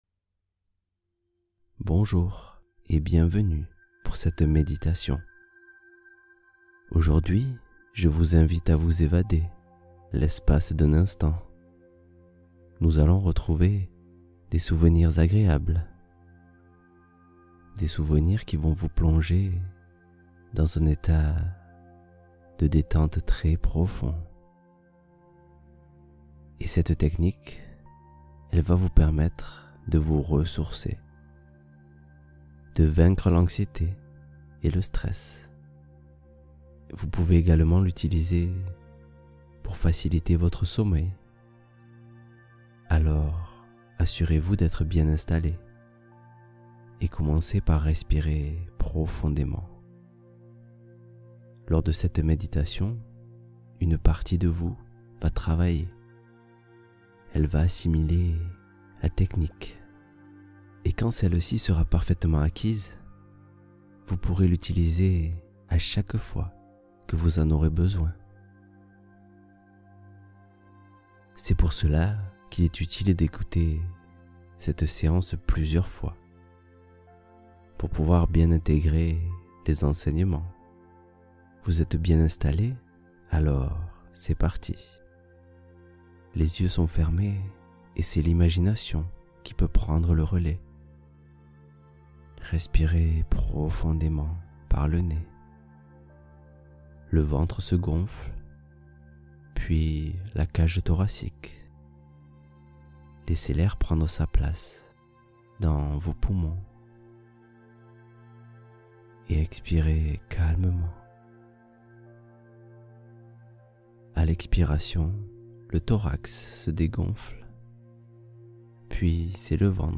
Histoire d’endormissement apaisante — Le Porteur d’Eau et l’acceptation de soi